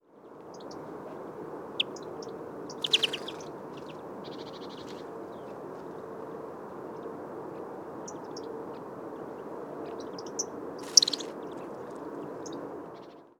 101018, European Greenfinch Chloris chloris, Yellowhammer Emberiza citrinella, flight calls, Altenfeld, Germany
cchloris_ecitrinella.mp3